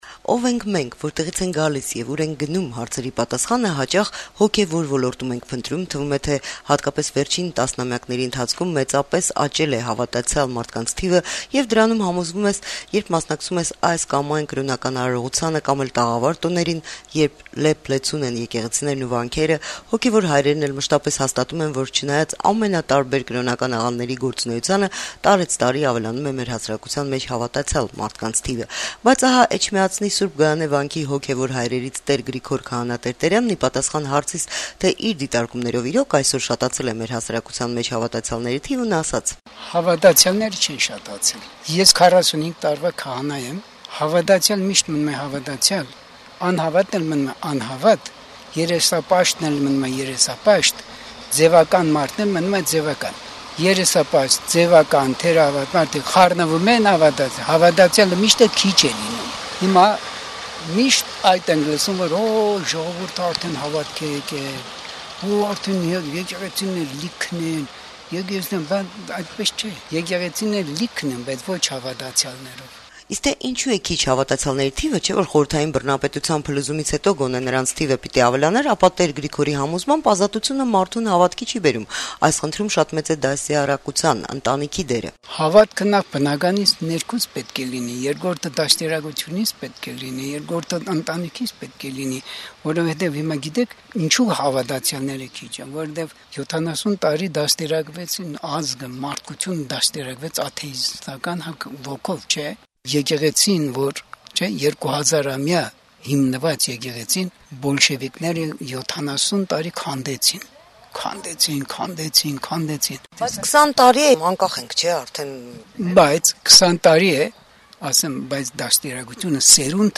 «Ազատություն» ռադիոկայանի հետ զրույցում